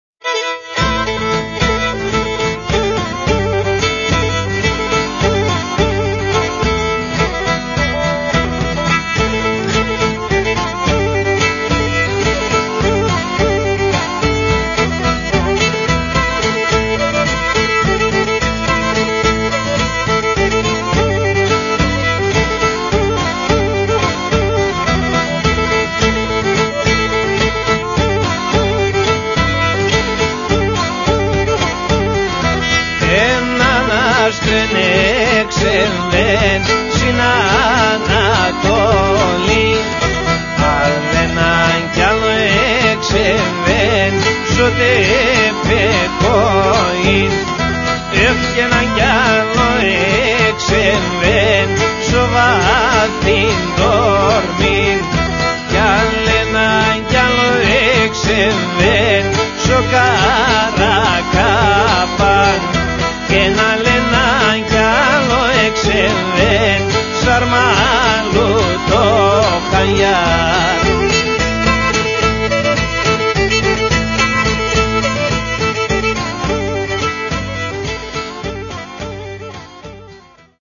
The dance that traditionally opens is the dipat or two step and is one of the oldest dance forms.